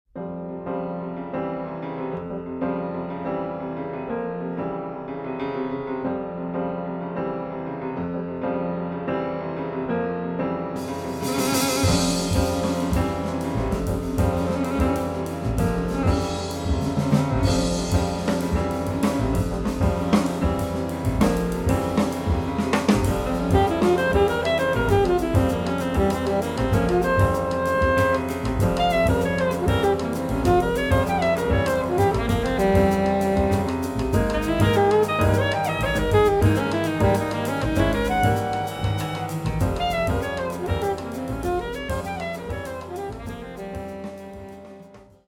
2011年２月にニューヨークでレコーディングされた